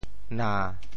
na7.mp3